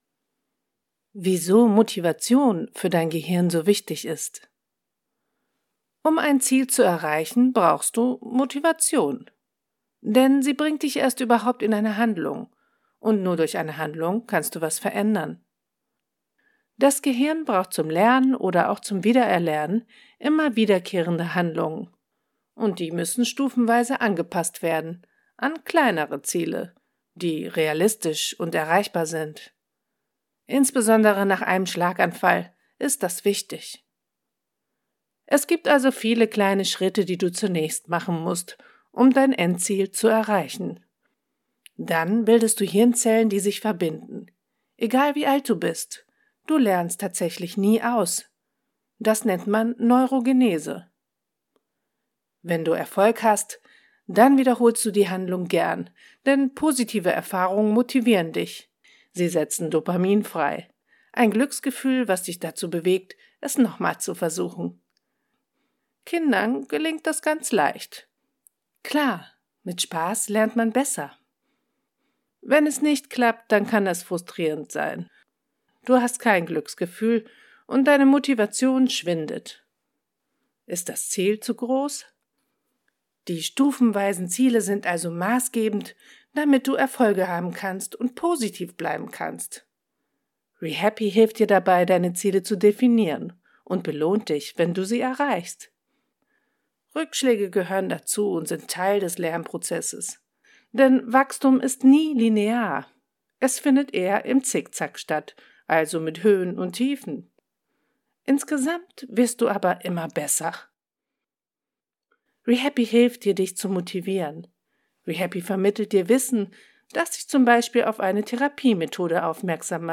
Sprechproben
informierend/ erklärend – Erklärfilm